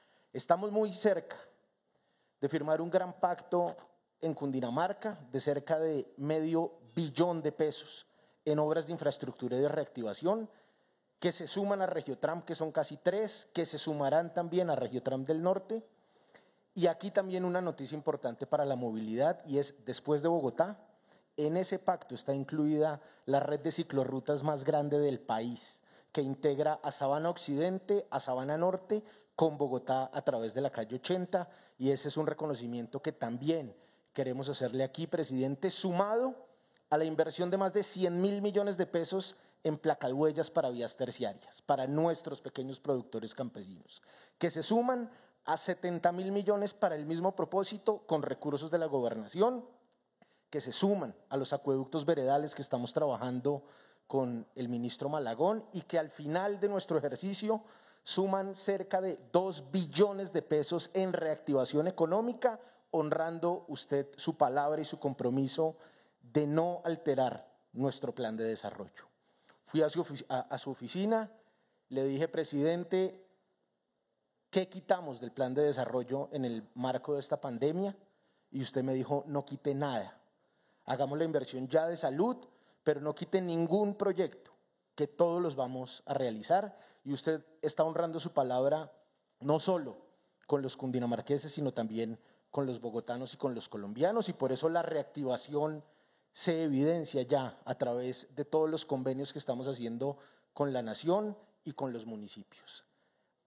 [AUDIO] Así lo dijo el gobernador Nicolás García en el marco del anuncio de la segunda línea del Metro, y que complementa la red de RegioTram del Norte y Occidente.
Declaraciones del gobernador de Cundinamarca, Nicolás García.